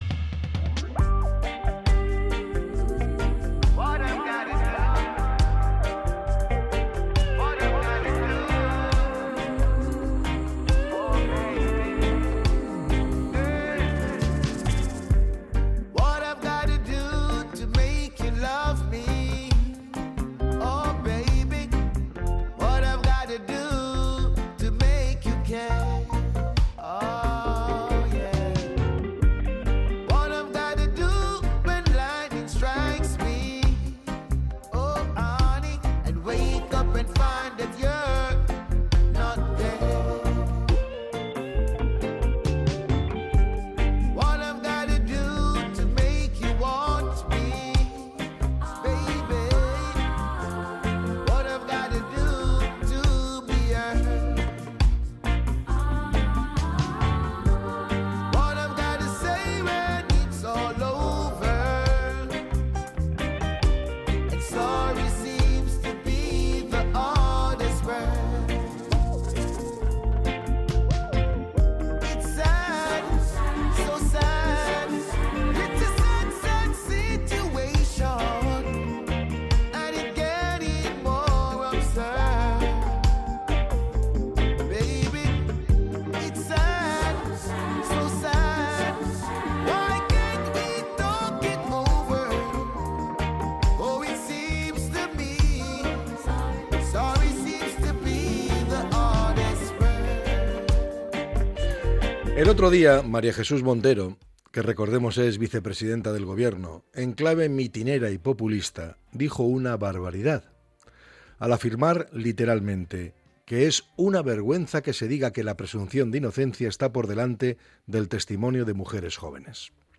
Podcast Opinión